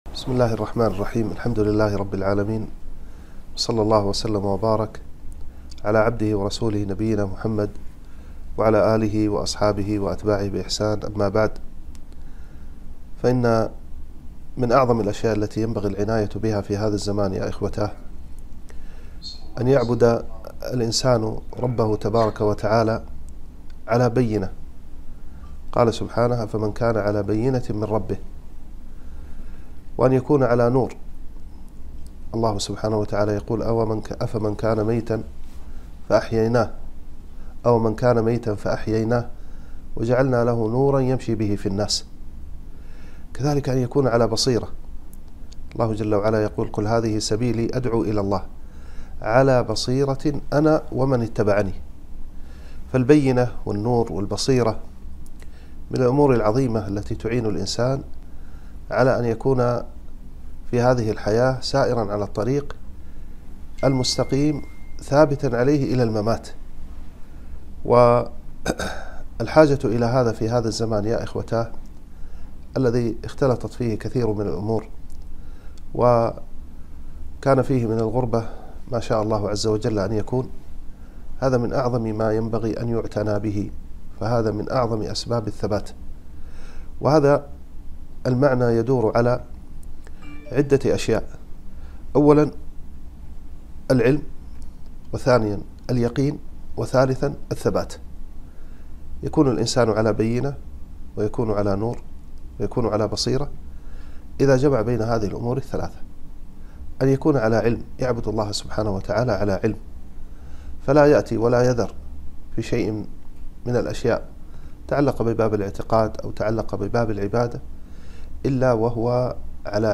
كلمة - وصايا مهمة للمؤمنين زمن الفتن